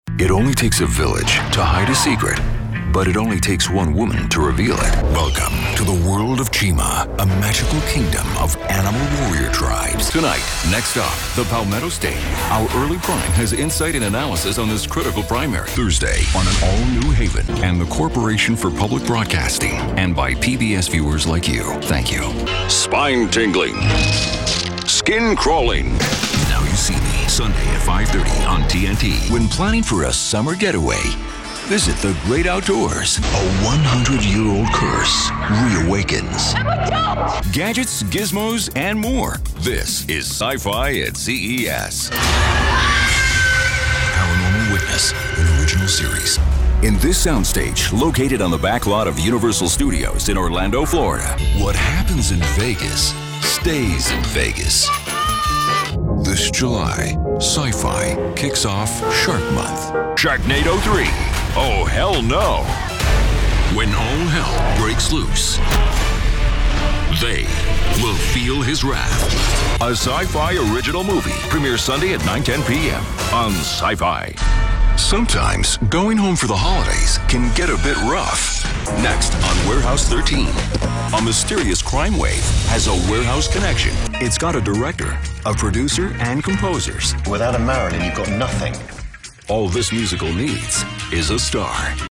Mature Adult, Adult Has Own Studio
Location: New York, NY, USA Languages: english 123 Accents: standard us Voice Filters: VOICEOVER GENRE: documentary promos VOICE CHARACTERISTICS: authoritative